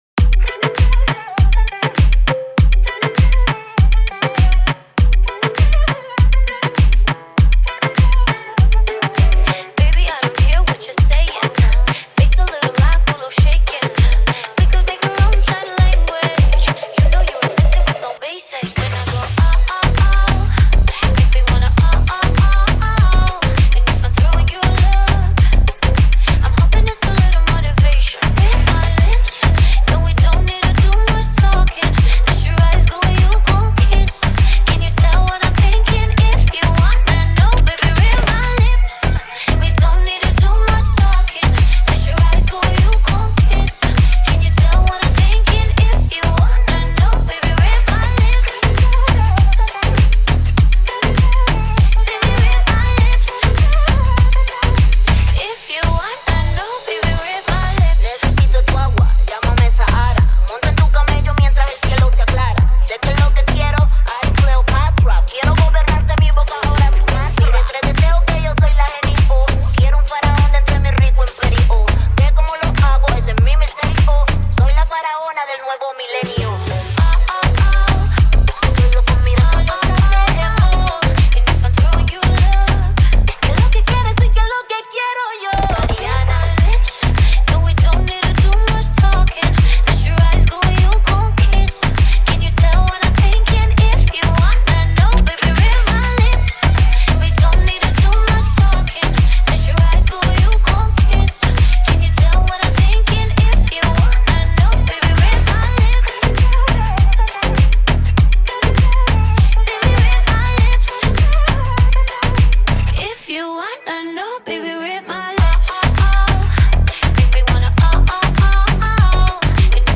это зажигательный трек в жанре поп-электроника
энергичные ритмы и мелодичные вокалы